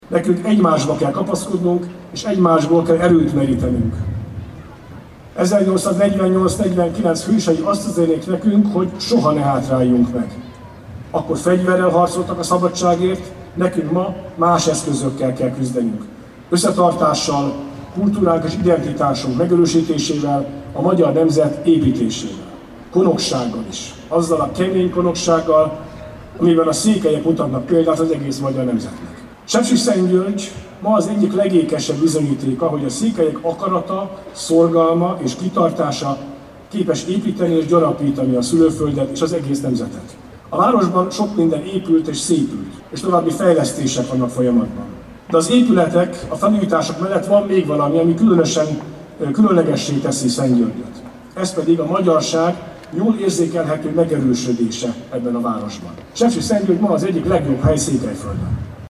Egymásba kell kapaszkodni és egymásból kell erőt meríteni, ezt üzenték március 15-i szónokok Sepsiszentgyörgyön.